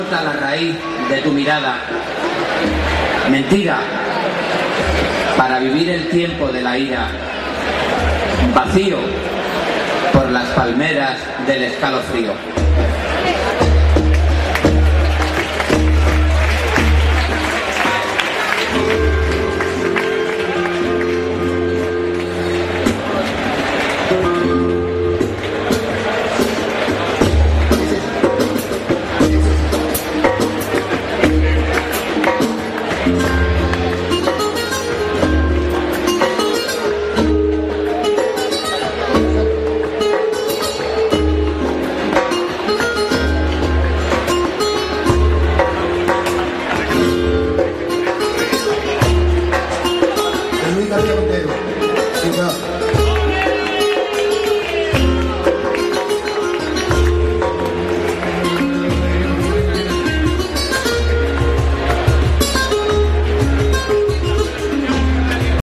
Actuación de Luis García Montero y Enrique Morente